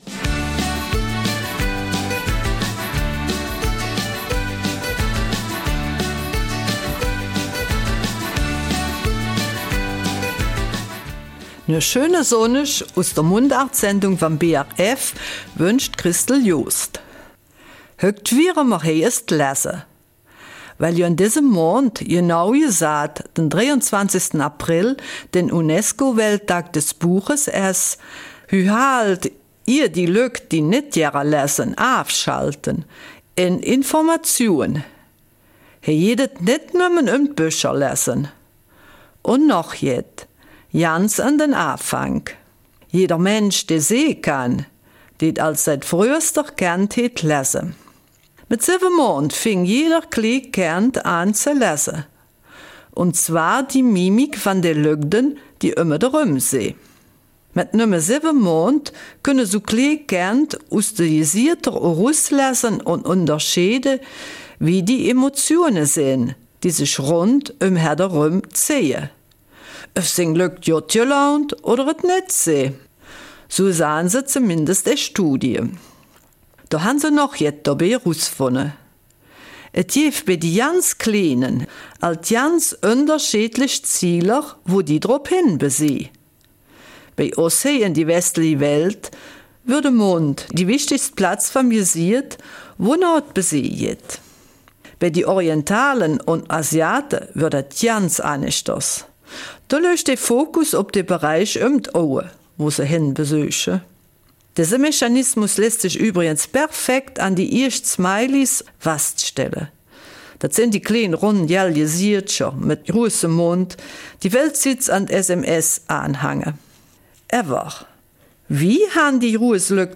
Eifeler Mundart: Lesen
Das Thema der Mundartsendung vom 14. April ist Lesen.